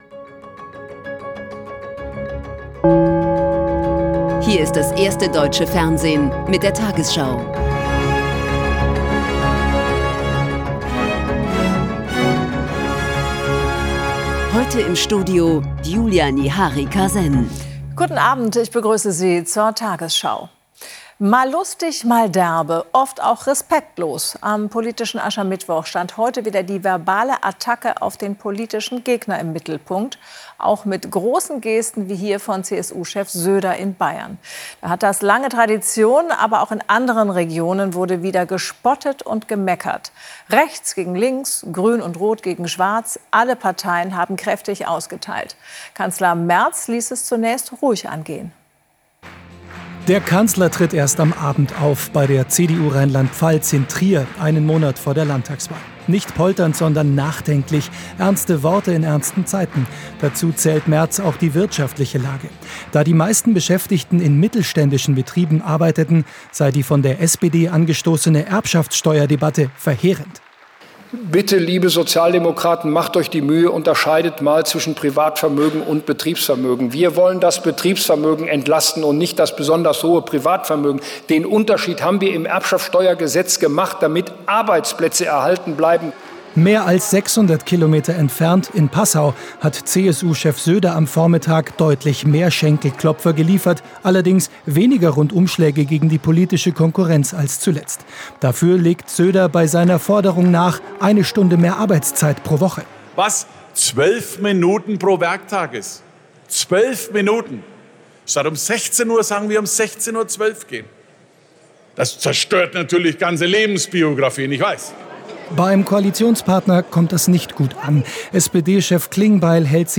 tagesschau: Die 20 Uhr Nachrichten (Audio)